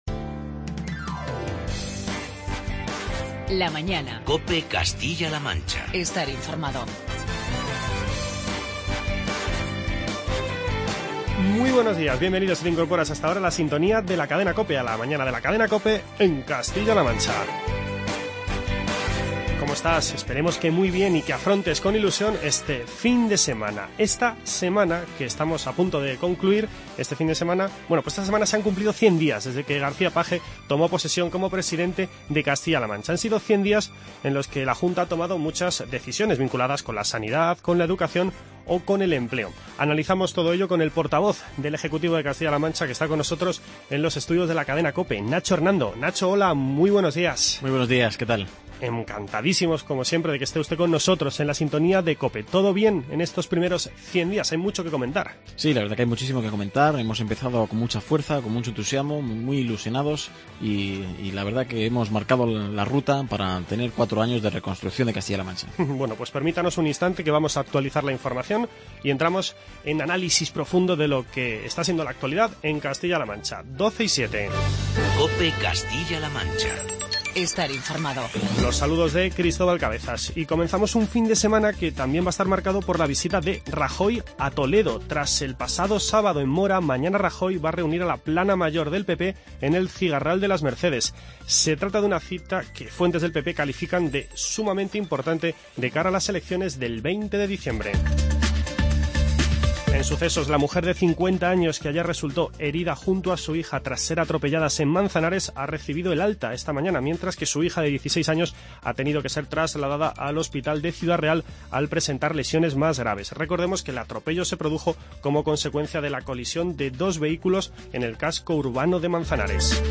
Charlamos con el portavoz del Ejecutivo, Nacho Hernando.